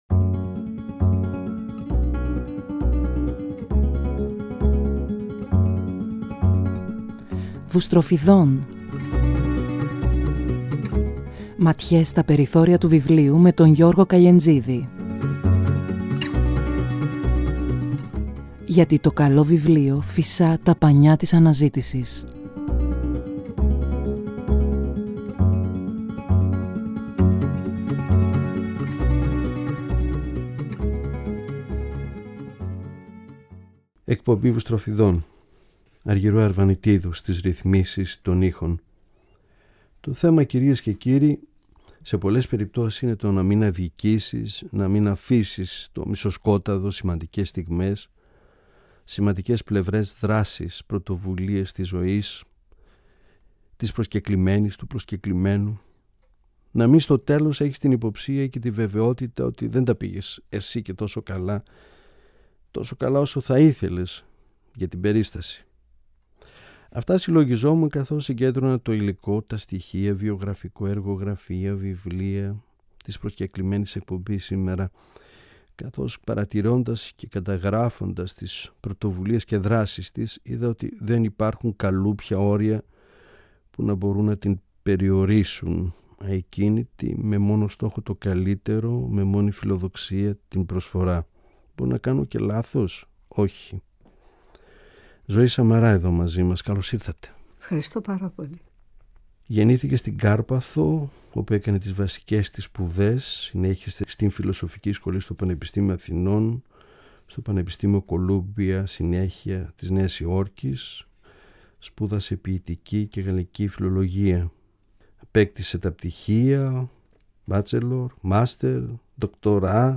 διαβάζει ποιήματά της και μιλά για την ποίησή της